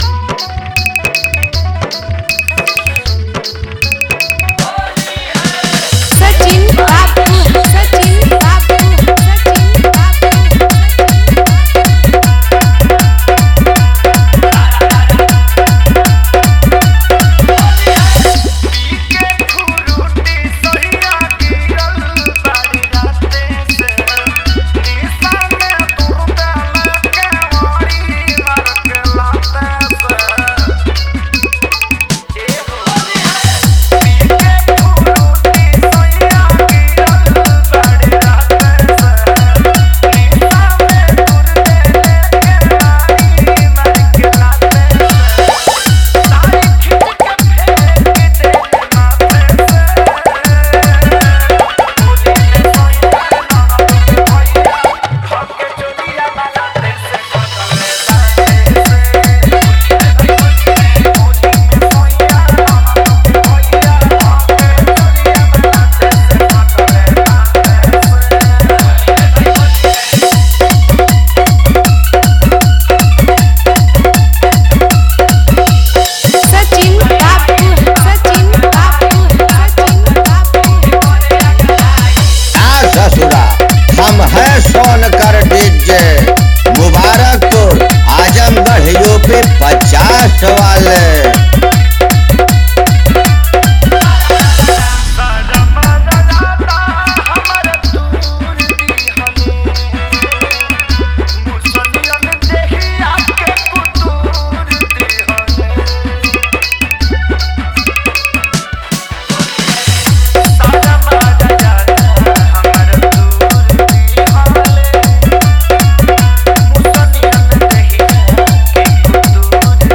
Holi Dj Remix Song Download